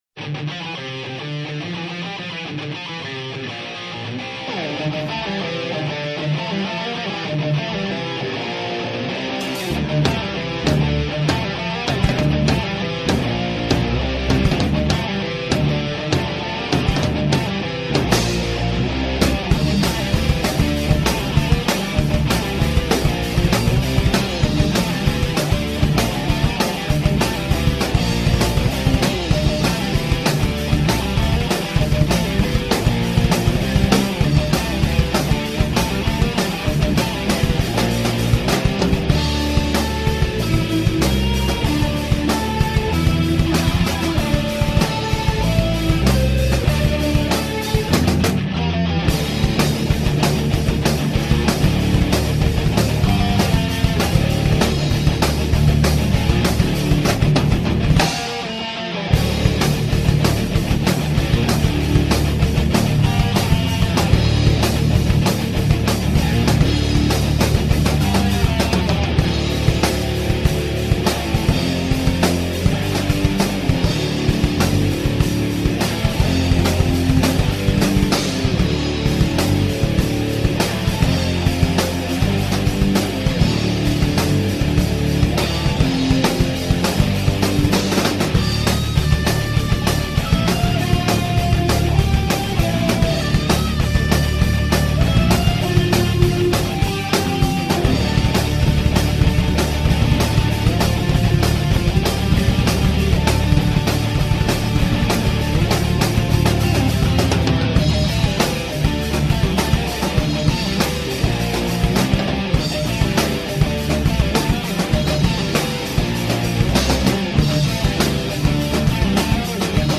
Gitarre
Drum
Bass